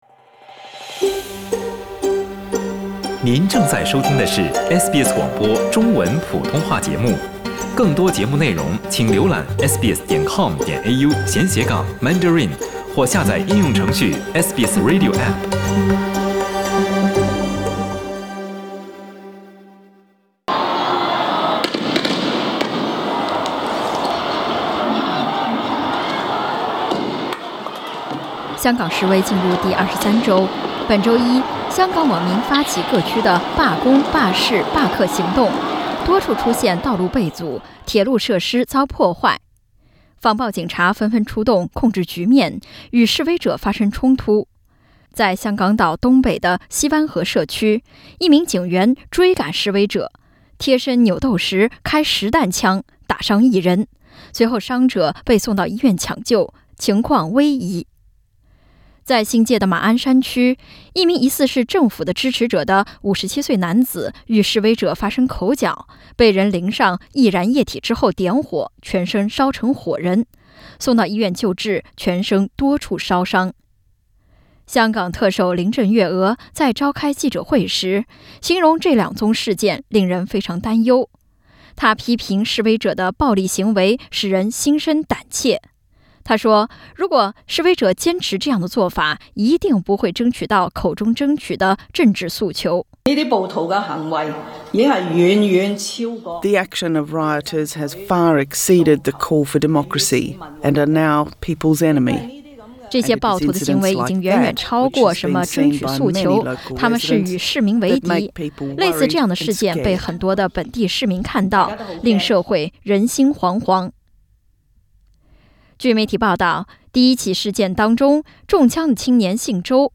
香港特区行政长官林郑月娥在一次新闻发布会上，谴责示威者的暴行，她说，激进示威者发起“三罢”（罢工、罢市、罢课）令香港受暴力冲击影响，社会人心惶惶 。
Hong Kong Chief Executive Carrie Lam, center, speaks during a press conference in Hong Kong, Monday, Nov. 11, 2019.